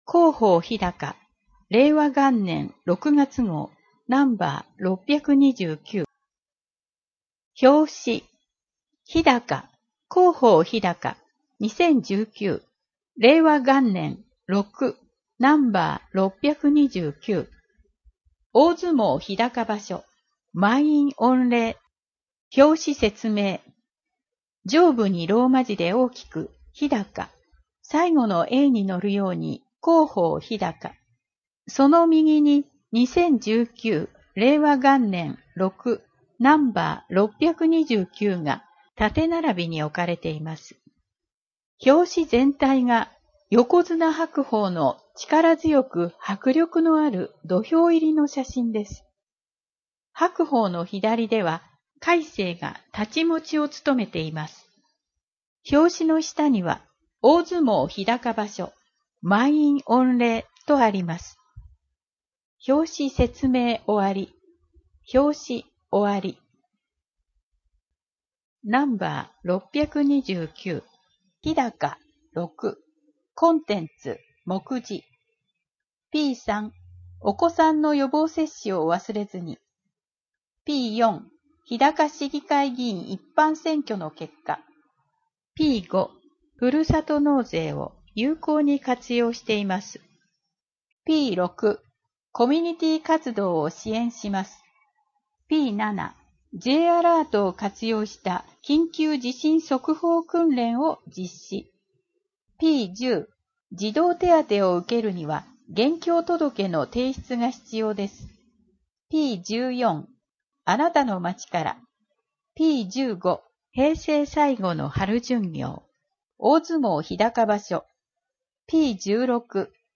朗読ボランティアグループ「日高もくせいの会」の皆さんのご協力により、「声の広報ひだか」を発行しています。